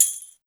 130JAMTAMB-L.wav